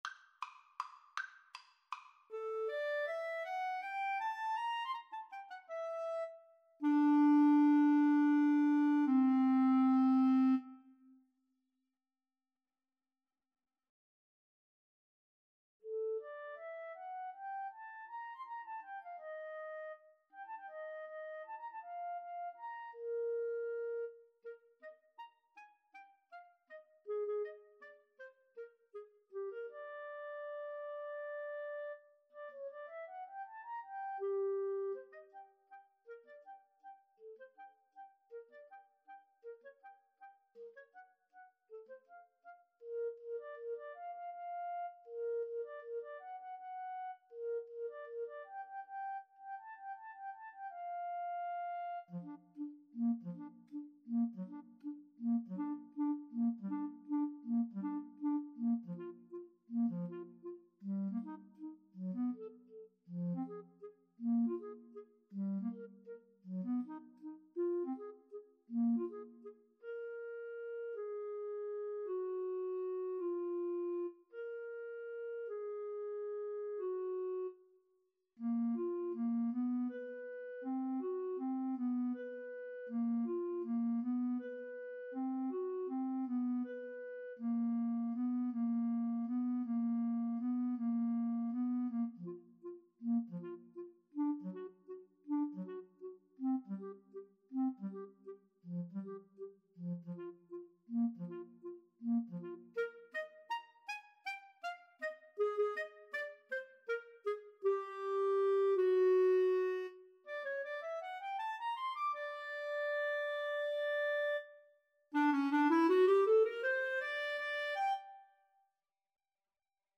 Allegretto = 160
3/4 (View more 3/4 Music)
Classical (View more Classical Clarinet Duet Music)